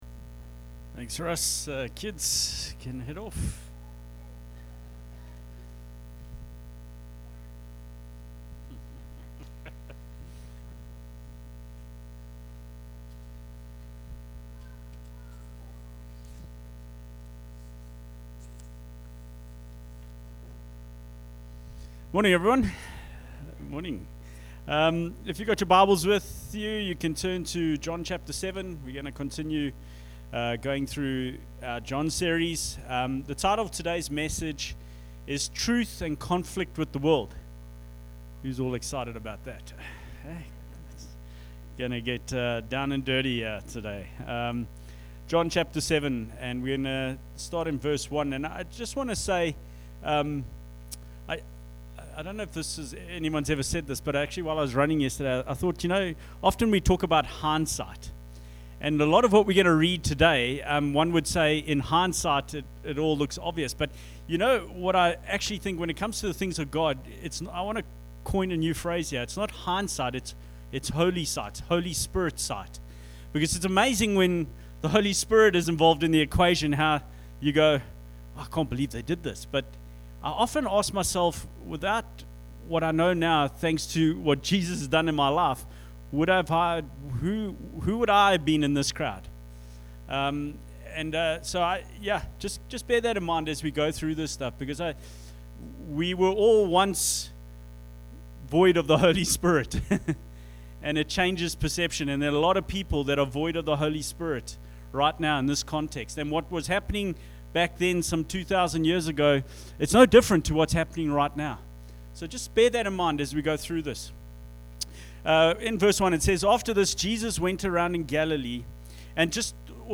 Sermons | Explore Life Church